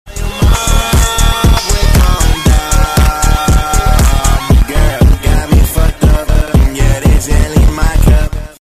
Tyrannosaurus Rex…